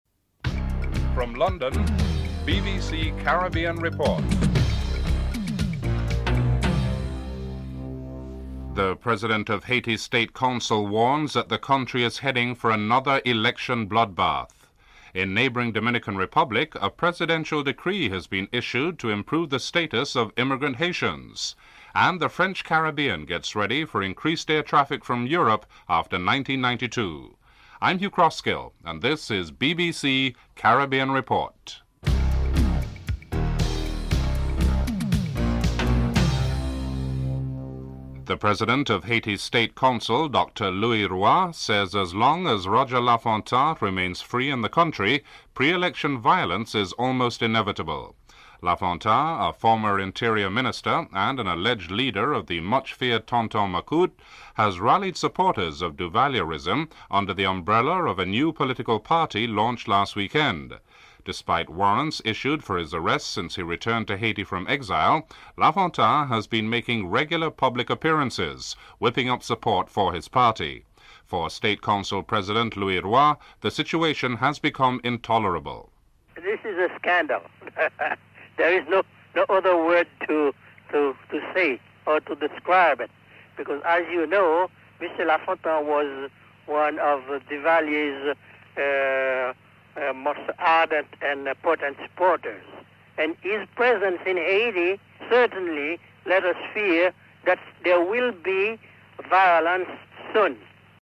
1. Headlines (00:00-00:40)
6. At the West Indian Committee Annual Lecture in London, David Core, Jamaican Foreign Minister, states that the IMP and the World Bank programmes are doing more to hurt Caribbean economies than to help them (13:28-14:55)